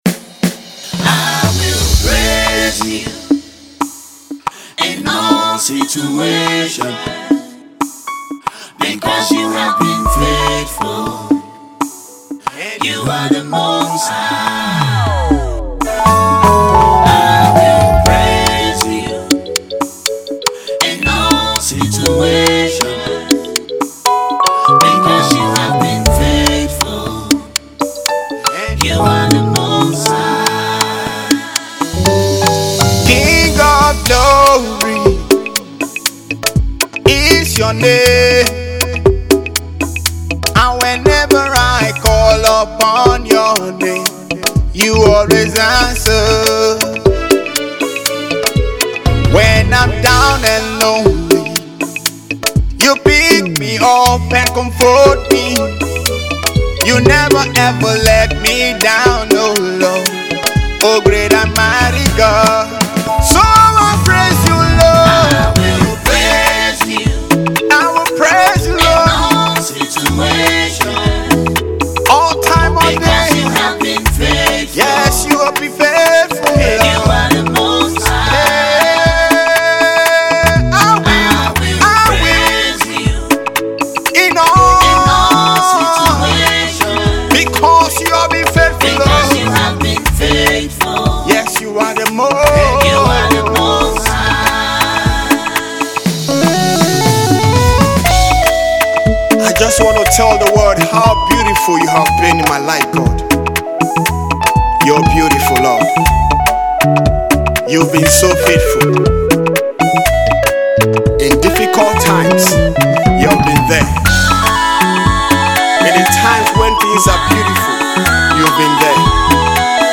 praise song
is a Nigerian born Gospel singer